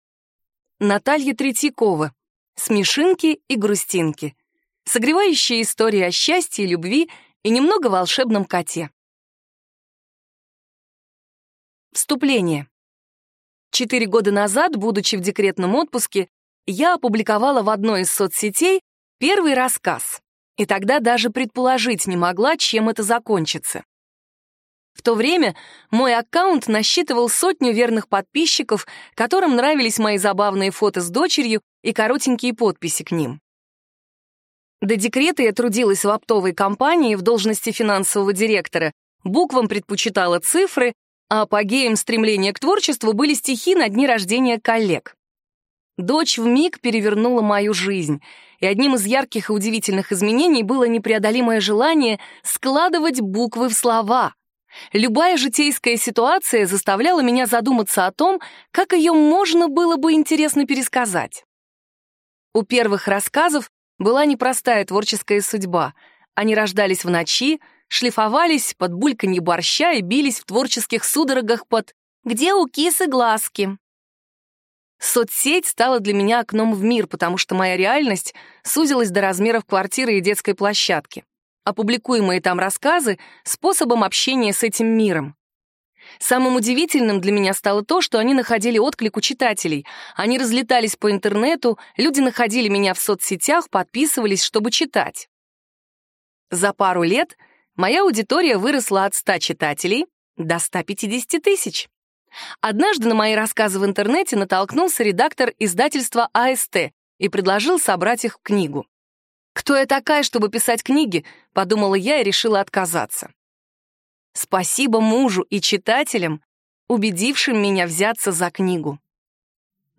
Аудиокнига Смешинки и грустинки. Согревающие истории о счастье, любви и немного волшебном коте | Библиотека аудиокниг